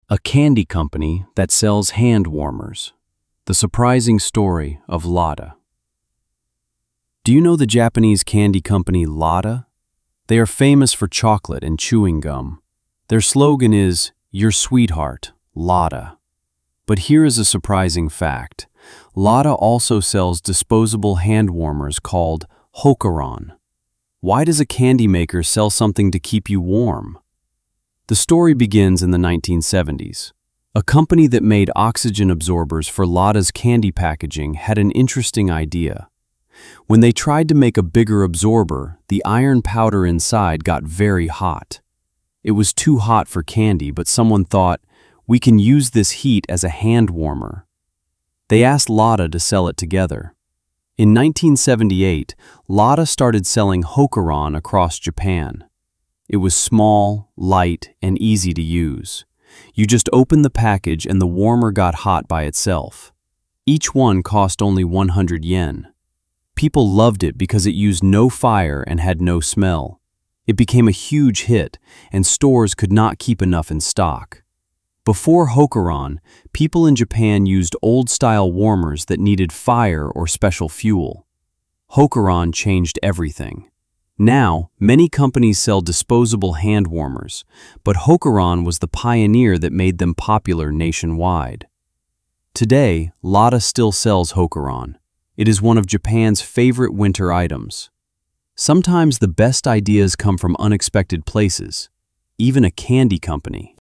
🔊 音読用音声